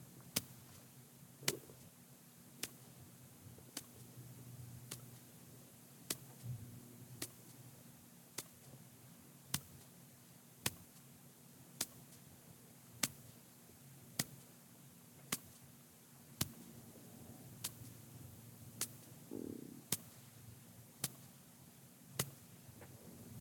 Hand Skin_Fingers_Pat_Soft - Hand_Body_Foley.ogg